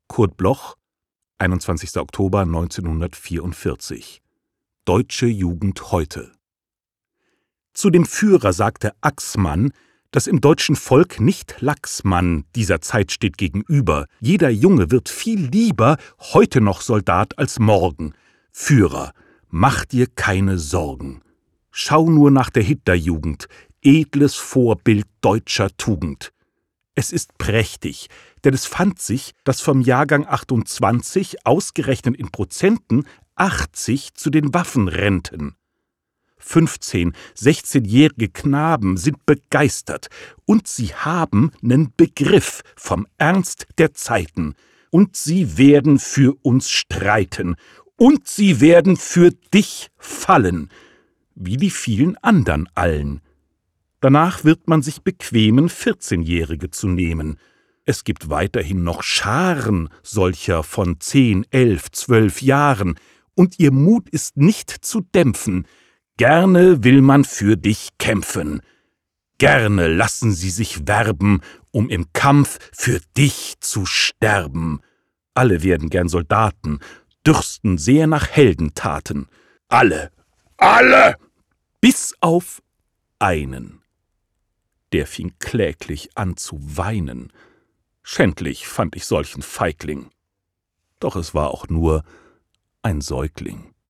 aufgenommen bei Kristen & Schmidt, Wiesbaden